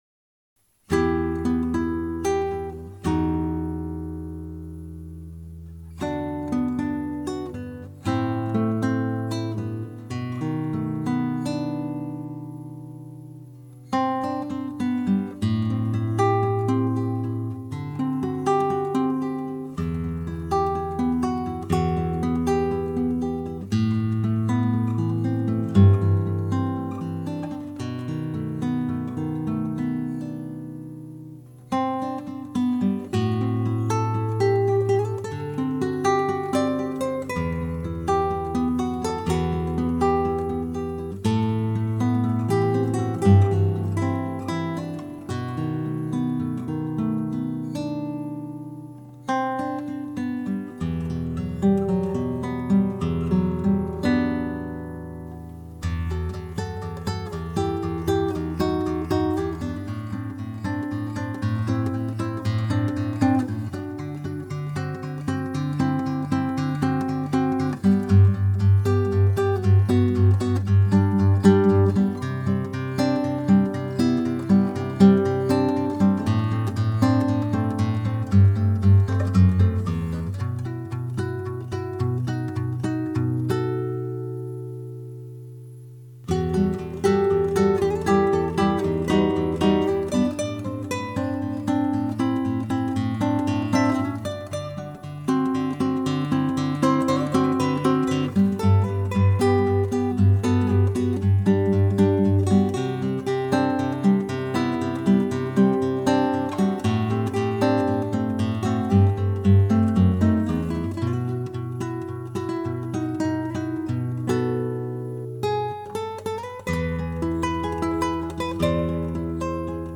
Ma matinée d'hier passée avec Esteve.....vous savez....une guitare..
:bravo: :bravo: :bravo: superbe , j'aime bien l'ambiance générale de ta pièce , très sympa
Merci beaucoup :bravo2: très joli, il y a comme un petit vent de liberté dans cette musique :bravo: